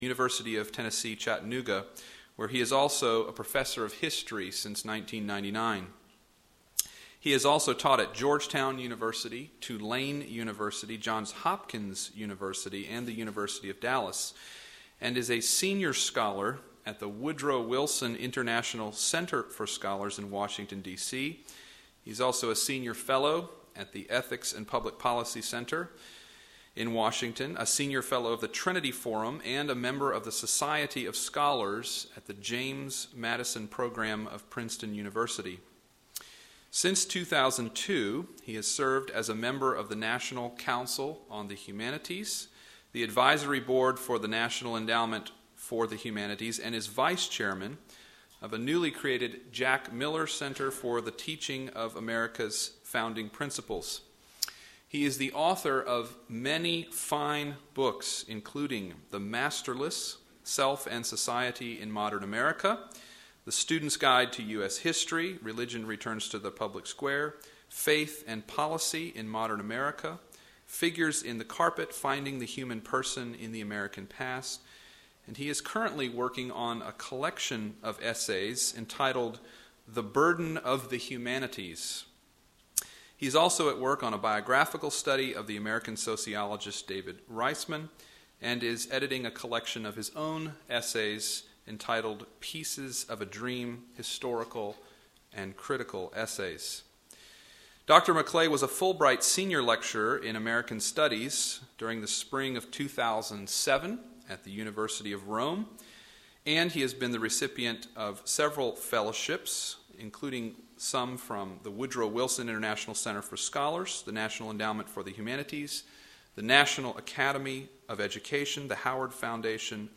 Mars Hill Forum Lecture Series: Wilfred McClay